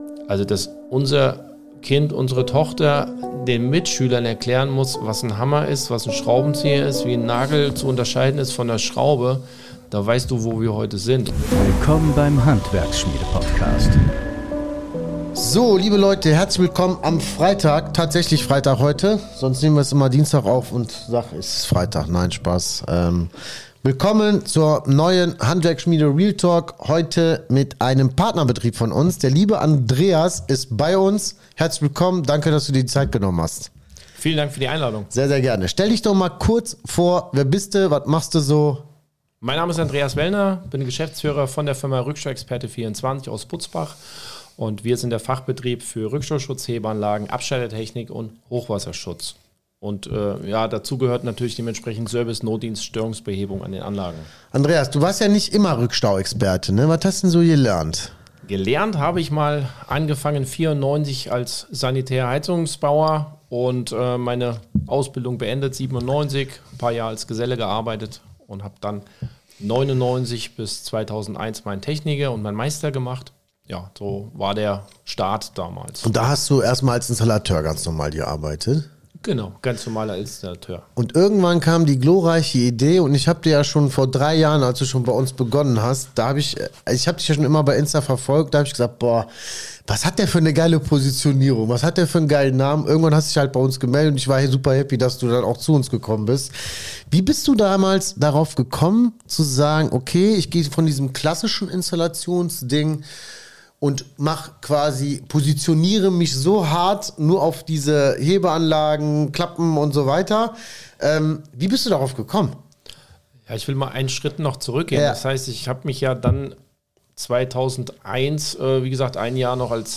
Ist die Generation Z verloren? | Interview